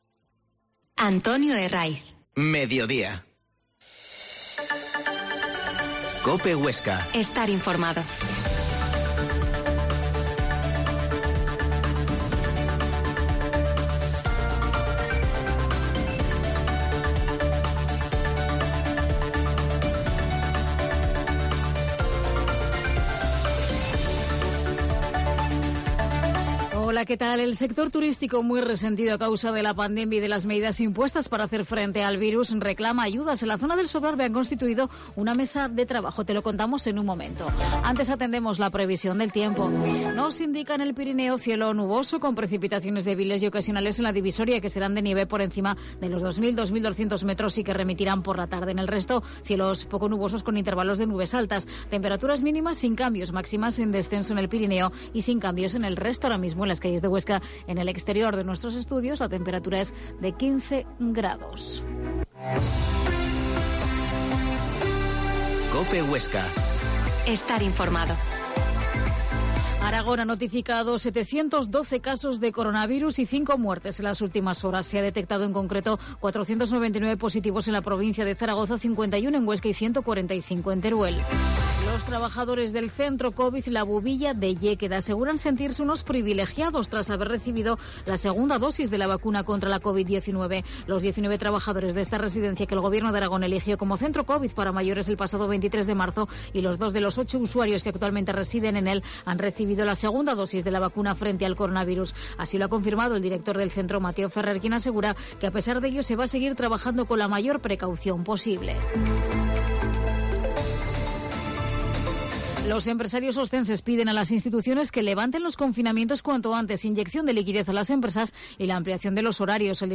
Mediodia en COPE Huesca 13.20 Entrevista al Pte de la comarca de Sobrarbe, José Manuel Bielsa